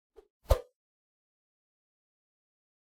meleeattack-swoosh-light-group05-01.ogg